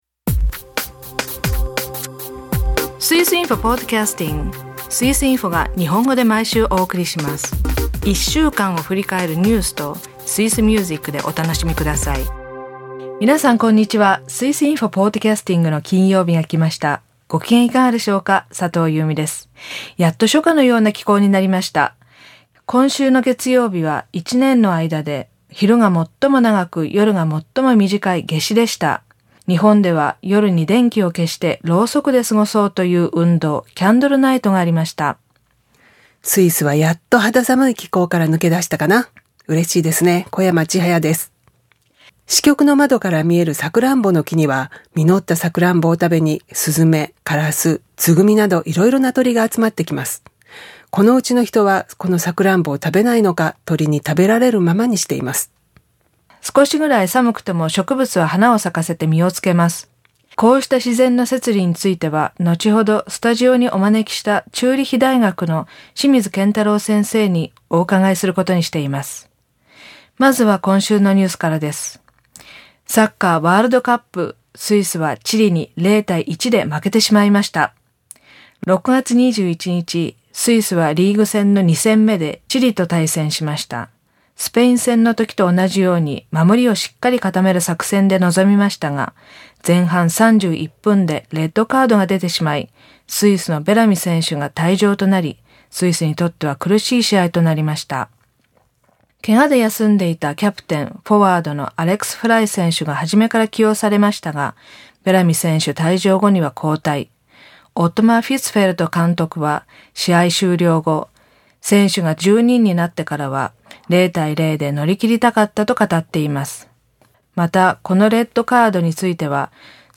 2009年、トップマネージャーの平均昇給率は20%に。歌は「Mir sind Schwiizer」。朗読、ウーリは主人の浪費癖を発見。